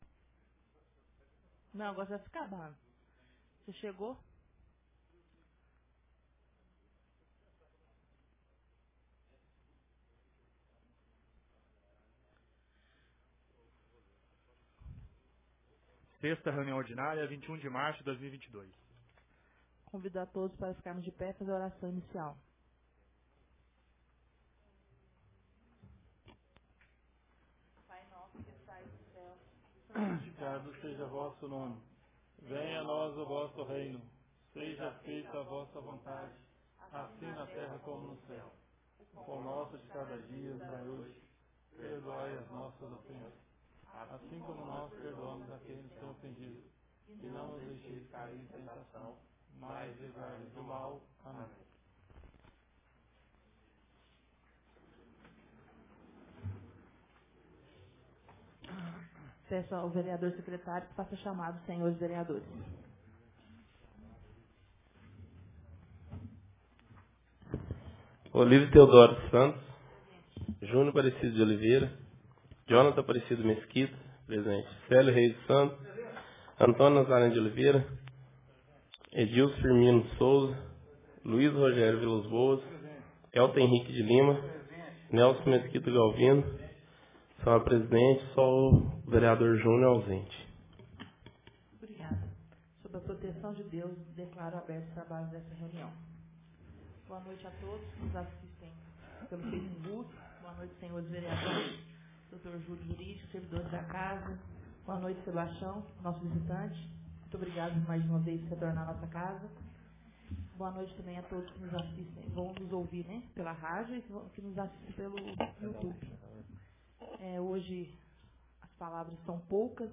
Ata da 6ª Reunião Ordinária de 2022